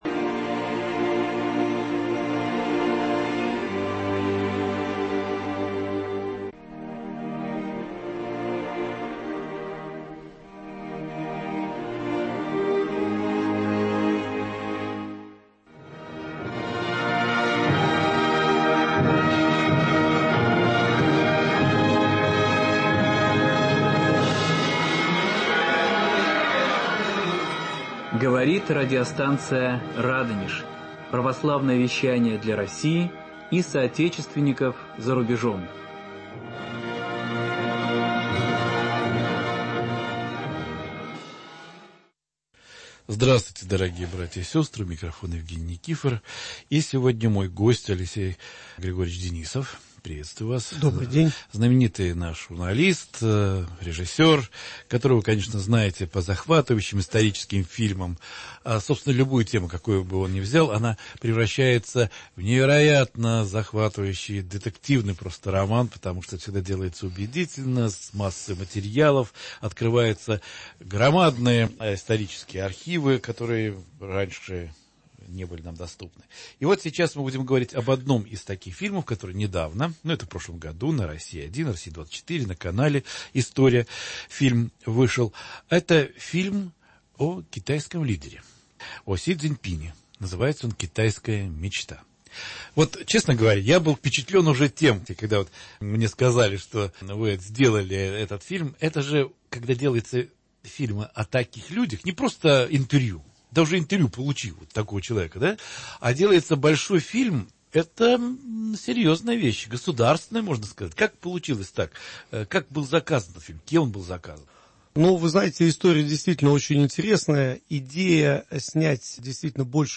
Эфир от 22.03.2019 22:00 | Радонеж.Ру